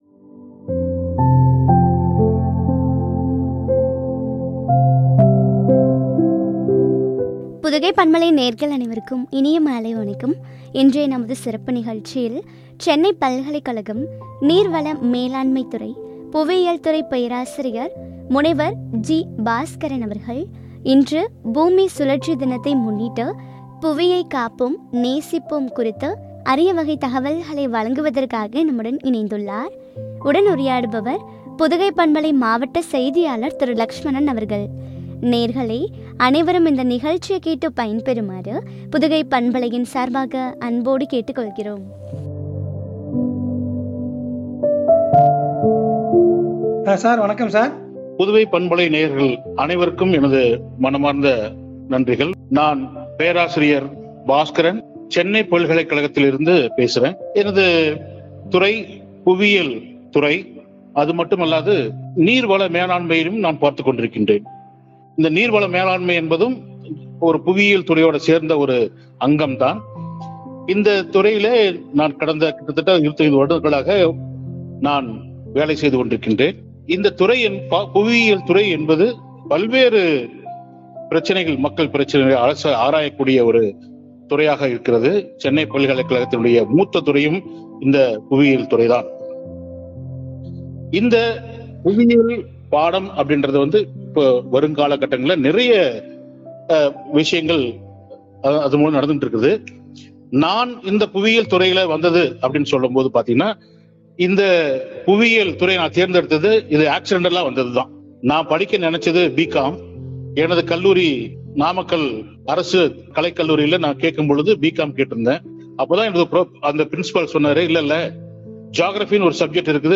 நேசிப்போம்” குறித்து வழங்கிய உரையாடல்.